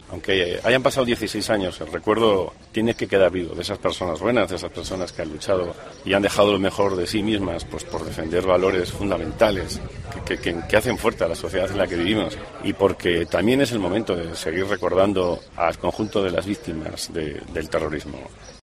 Declaraciones del presidente del PP en Aragón Luís María Beamonte.